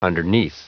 Prononciation du mot underneath en anglais (fichier audio)
Prononciation du mot : underneath